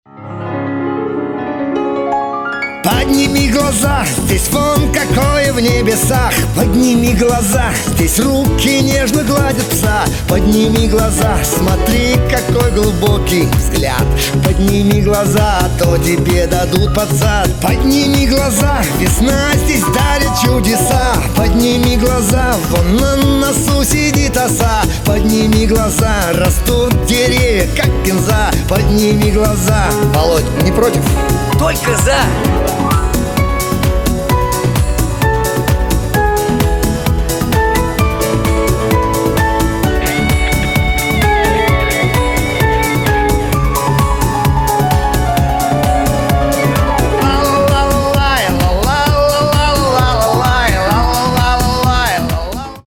• Качество: 256, Stereo
позитивные
мужской вокал
веселые
смешные
рояль